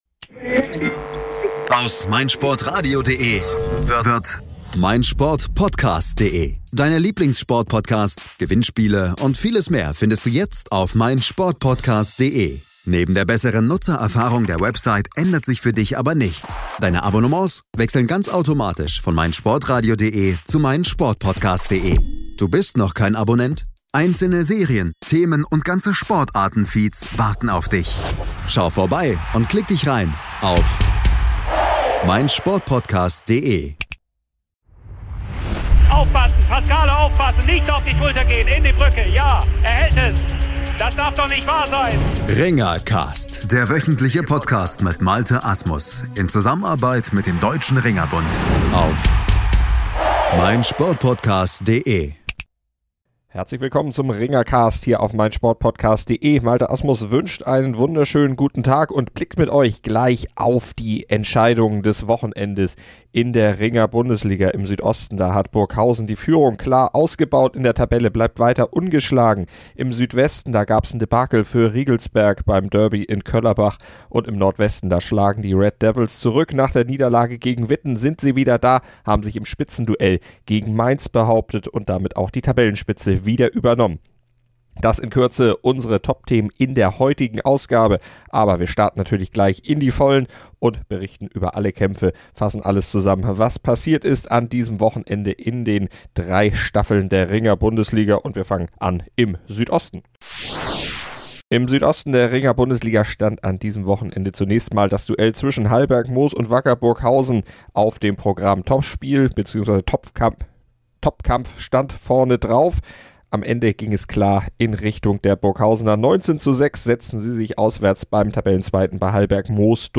Dazu gibt es Stimmen aus Hausen-Zell und noch viel, viel mehr.